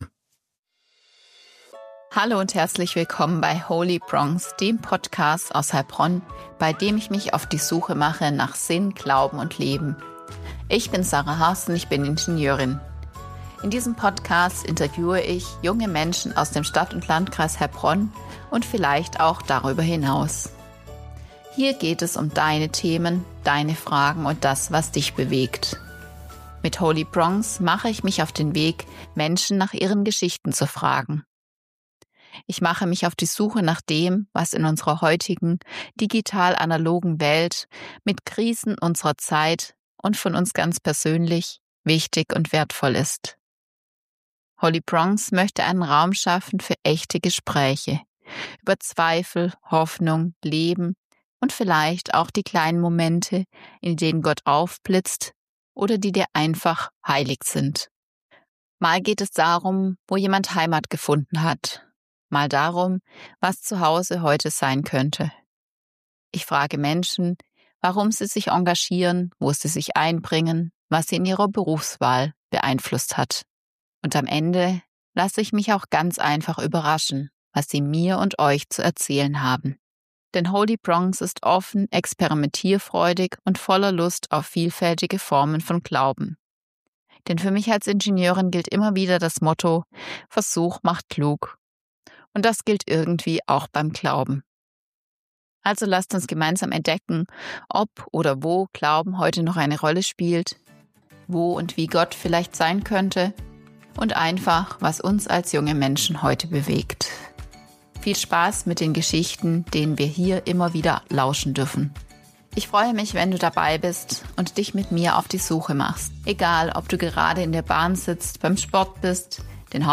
In diesem kurzen Trailer